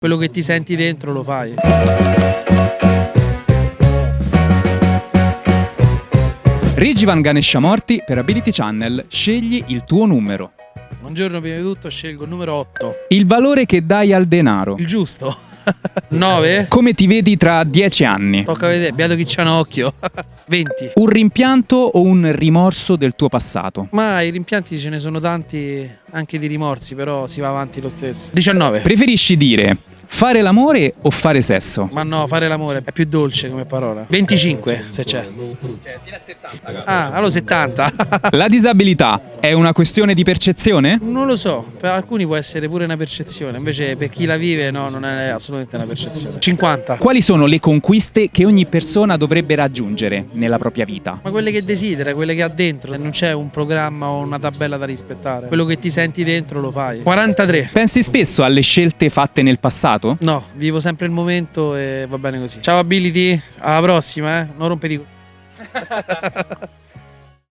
Agli atleti che abbiamo incontrato chiediamo di scegliere un numero da 1 a 70: sono domande senza filtri e variano dalle curiosità più leggere alle questioni scomode che fanno riflettere.